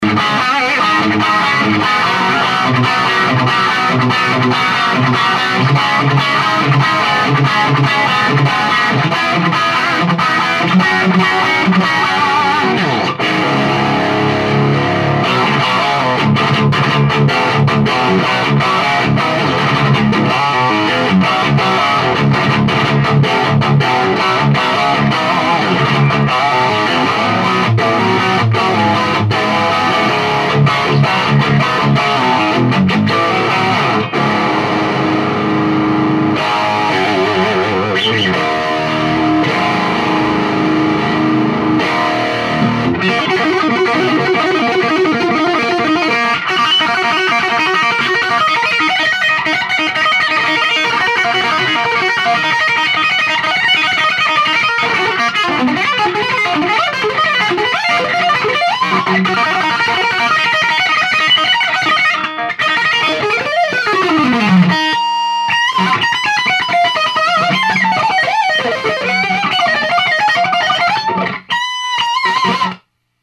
A strat,and used my PC sound card to record with,just a SM 58.